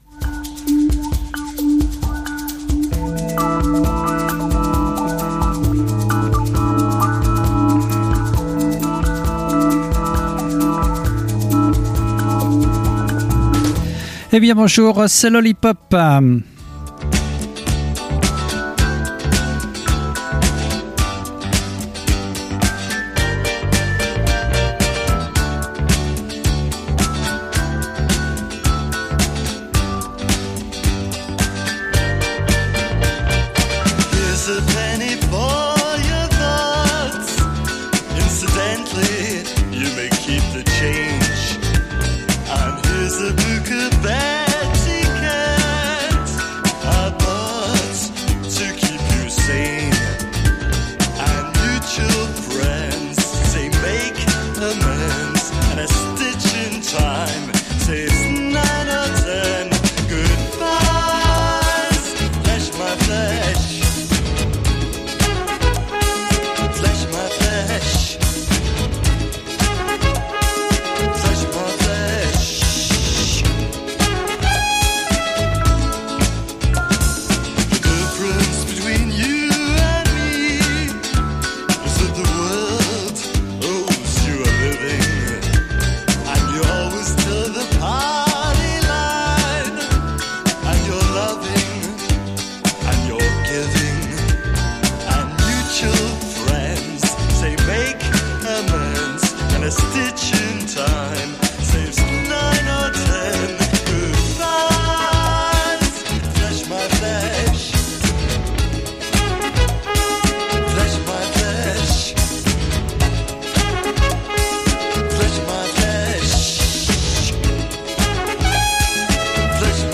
Toutes les nouveautés pop et indie pop du moment